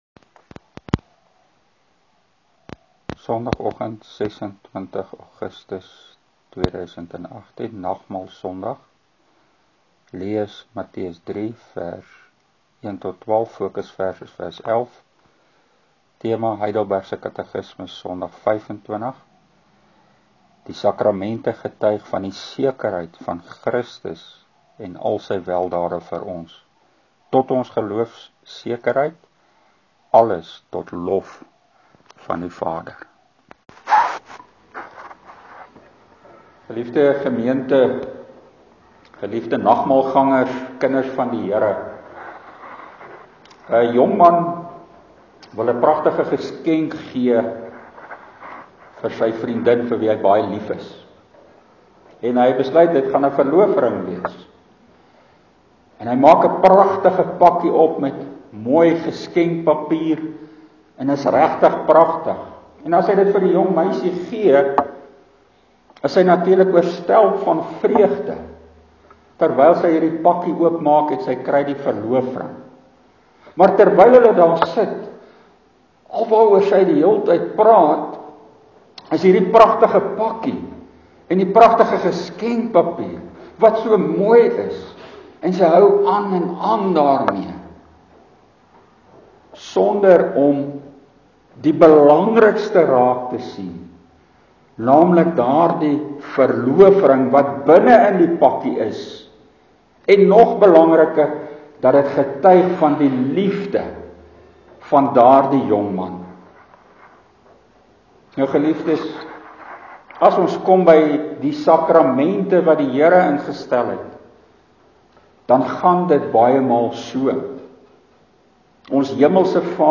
HK Nagmaalpreek: Die sakramente getuig van die sekerheid van Christus en al sy weldade, vir sy kinders
Preekopname (GK Carletonville, 2018-08-26)